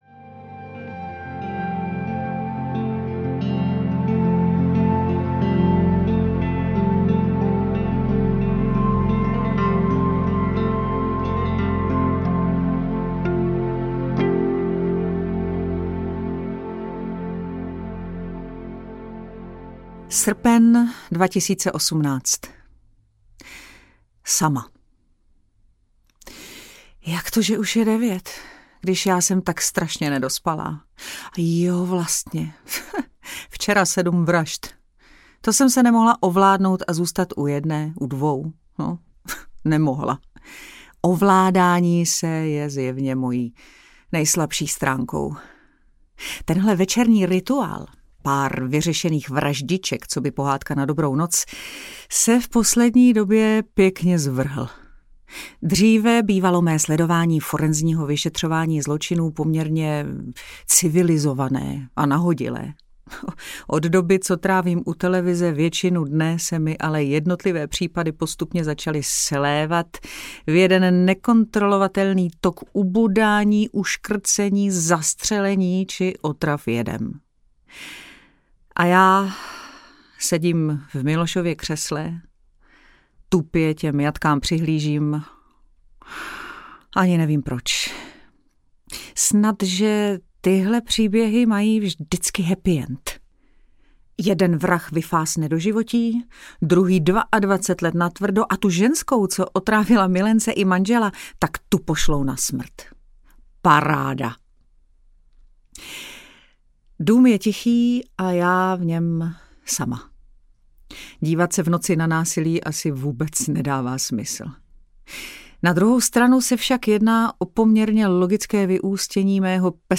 Nalakuj to narůžovo audiokniha
Ukázka z knihy
• InterpretBára Munzarová